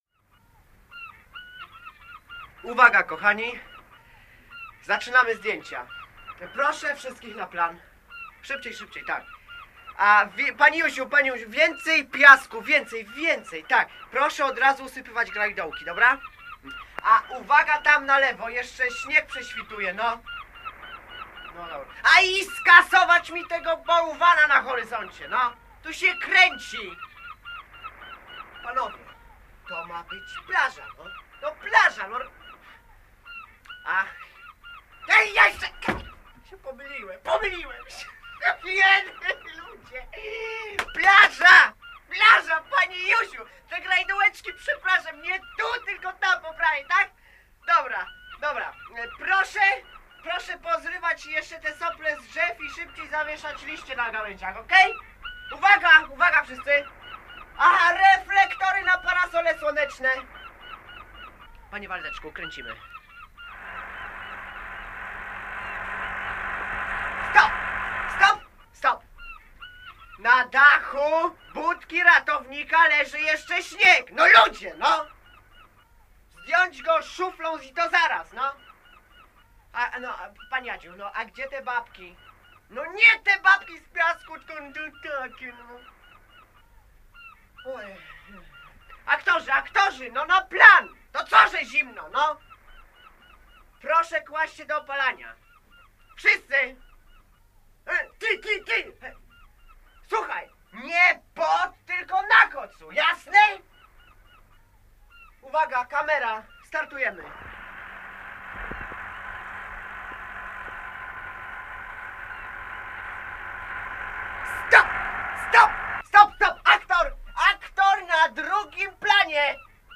Słuchowisko o kręceniu filmu na plaży